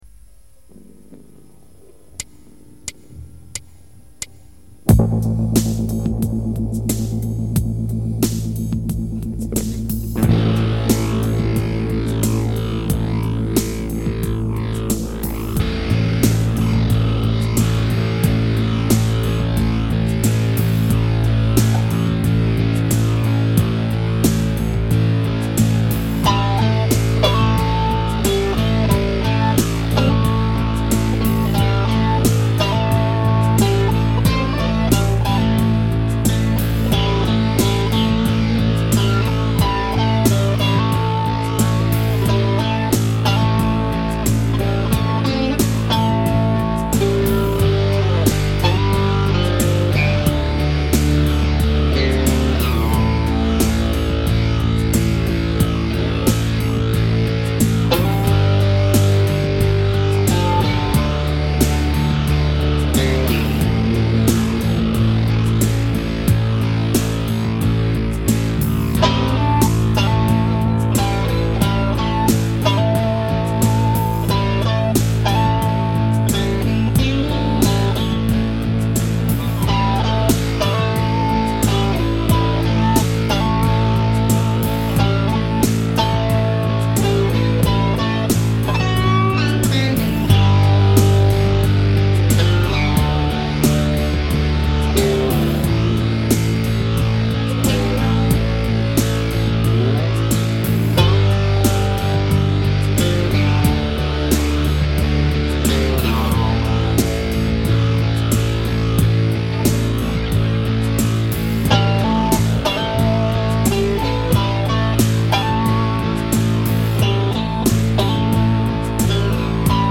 Back in 2007, I went on a recording kick, not knowing what the hell I was doing, with almost no gear whatsoever.
Reef Ridge Aeration: Another shuffly instrumental which proves I have exactly 45 seconds of second-rate lead guitar chops (hey, I’m really a rhythm guy, but I try…).